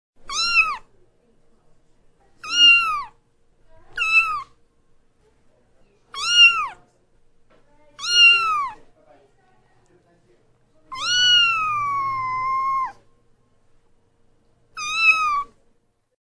Cat Meow